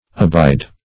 Abide \A*bide"\, v. t.